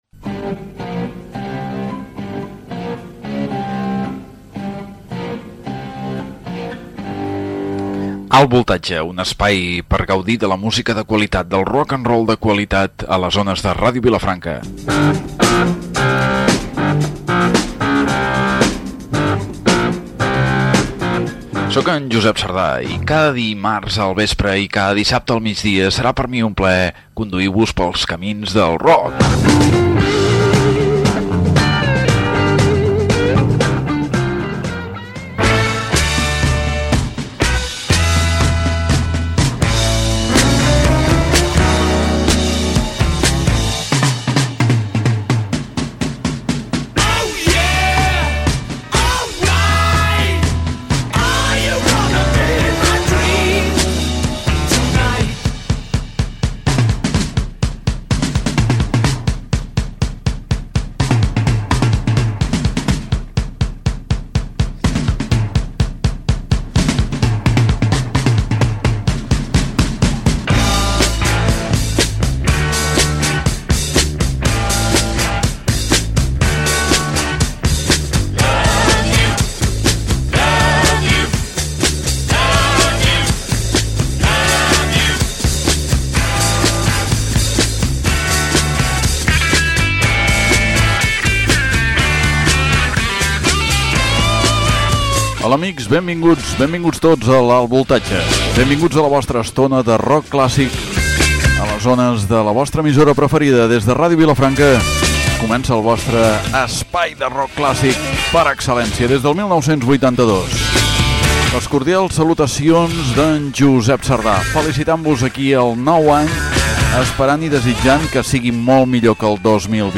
Identificació de l'emissora, presentació del programa, desig de bon any, tema musical del 2020
Musical
FM